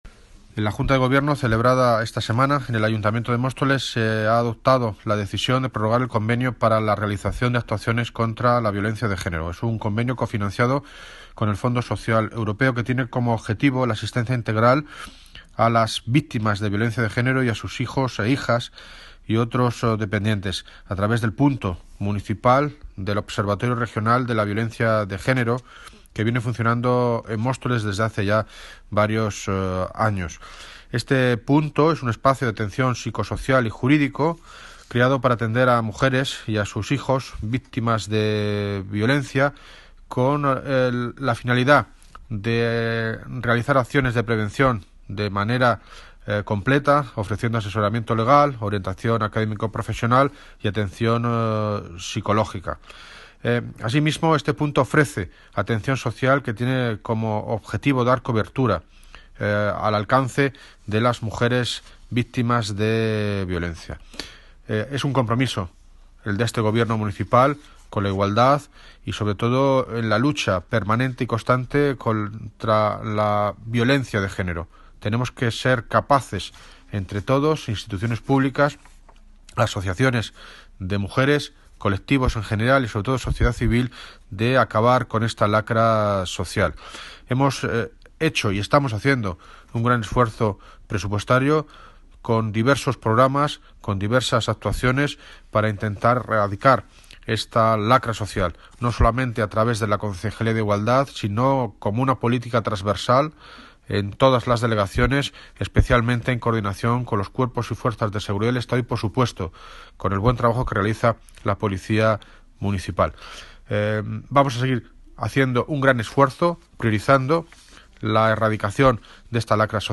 Audio - David Lucas (Alcalde de Móstoles) Sobre Convenio actuaciones Violencia de Género